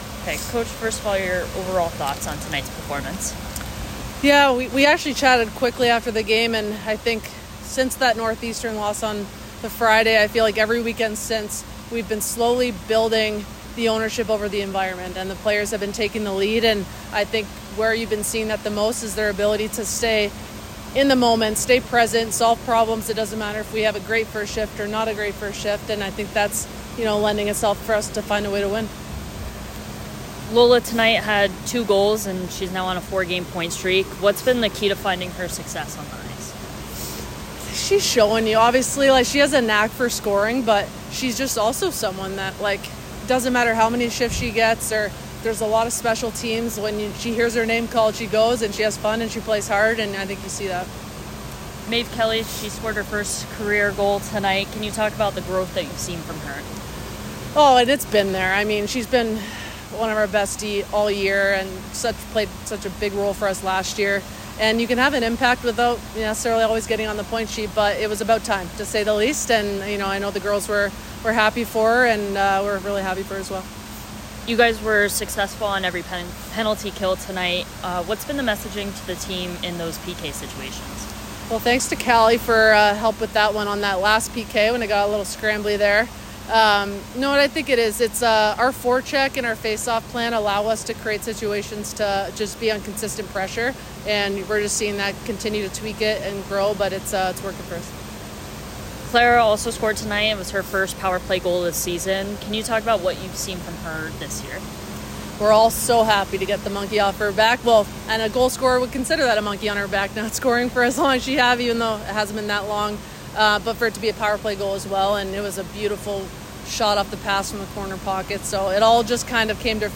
Syracuse Postgame Interview